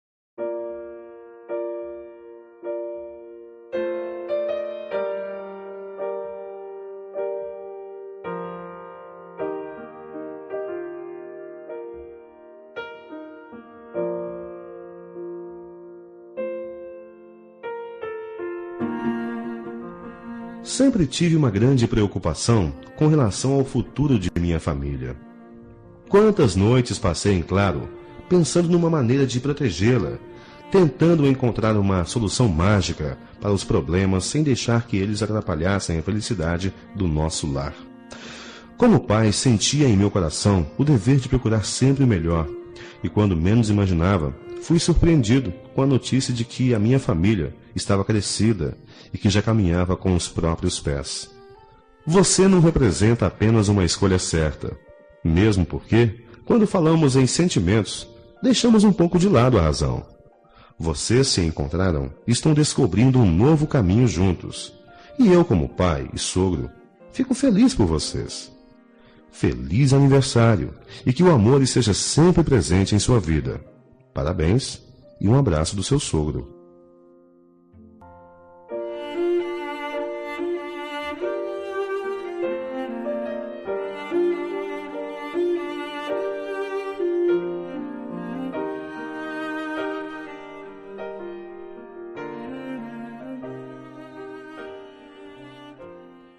Aniversário de Genro – Voz Masculina – Cód: 348960